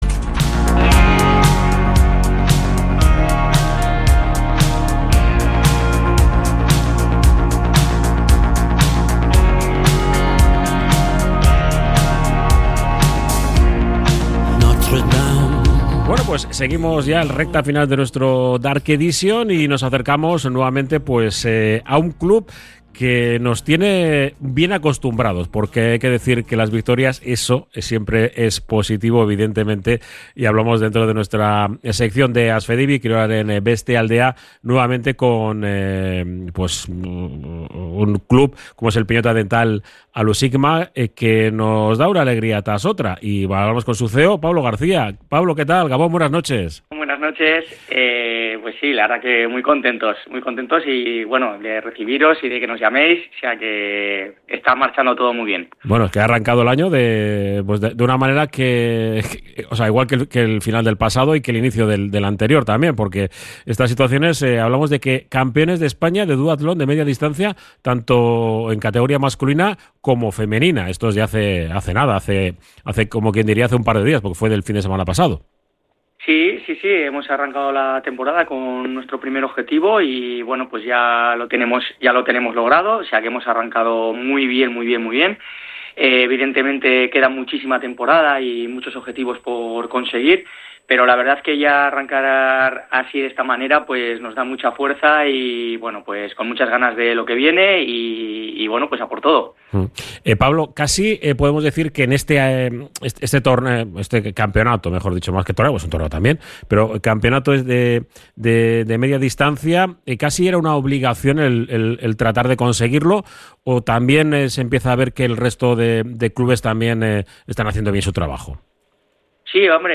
Hablamos con